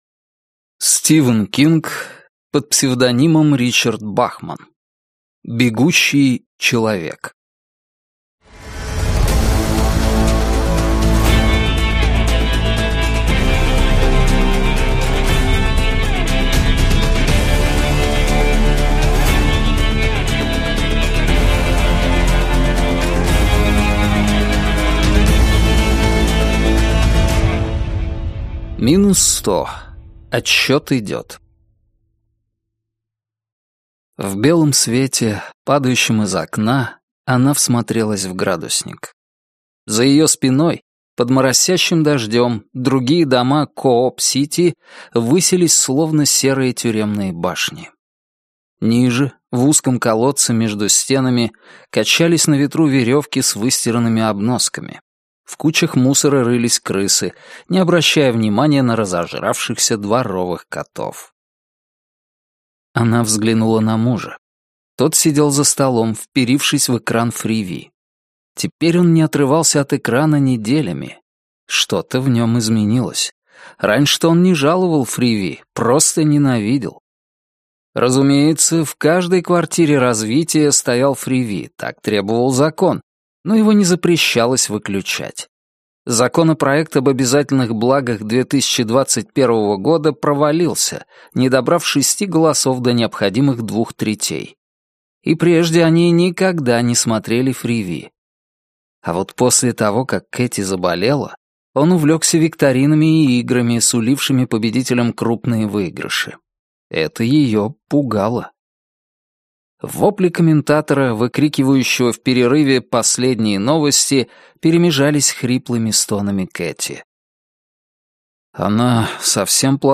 Аудиокнига Бегущий человек - купить, скачать и слушать онлайн | КнигоПоиск